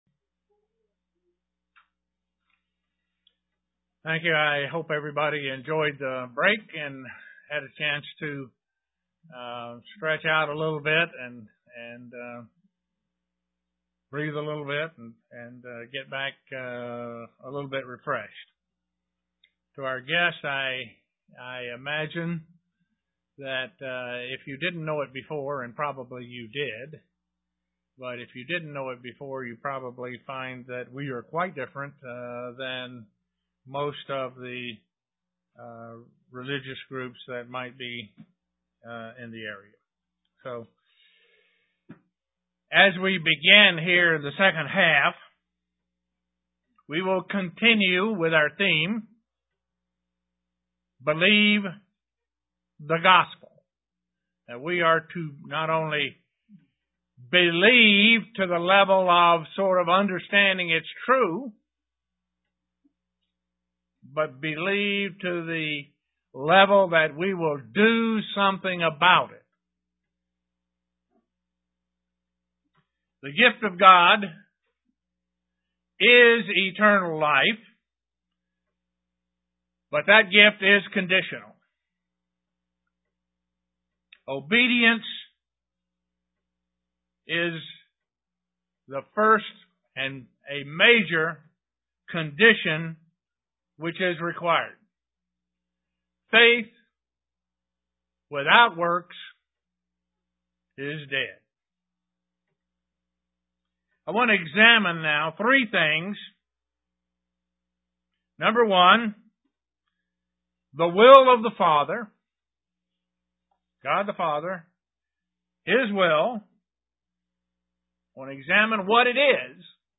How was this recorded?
Given in Elmira, NY